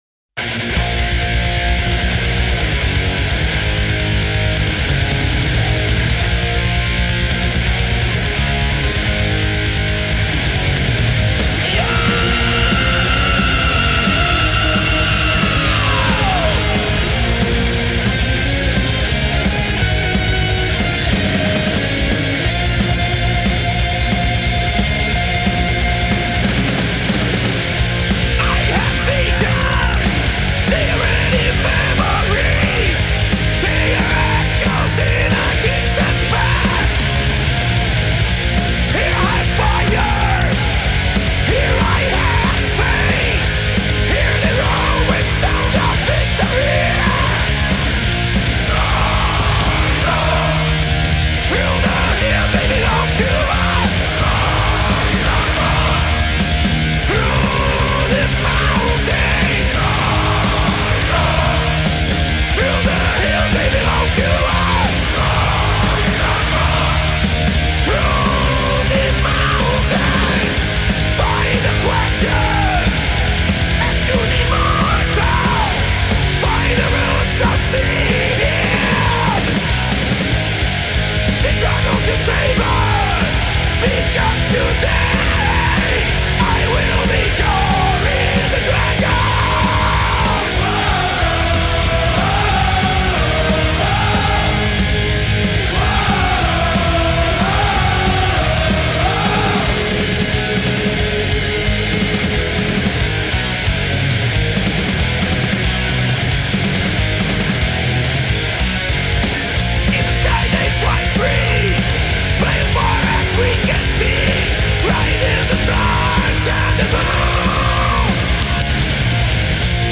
Heavy Metal